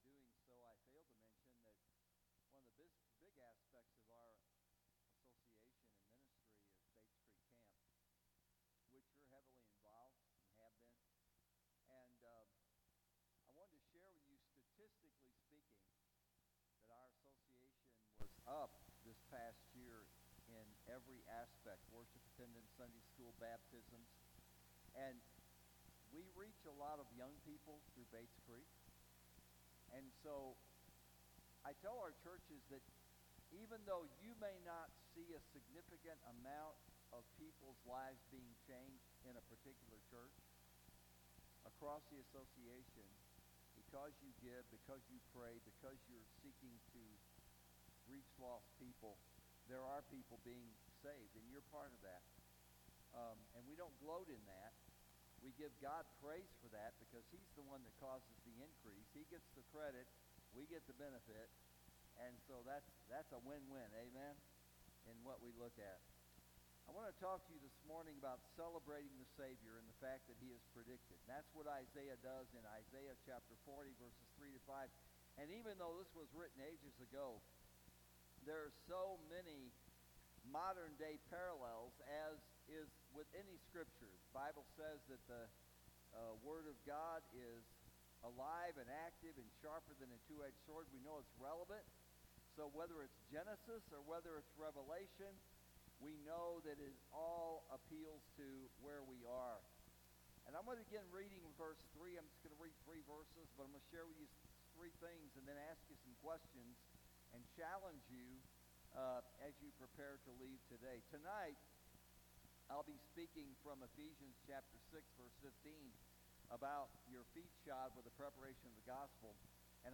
December-8-2024-Morning-Service.mp3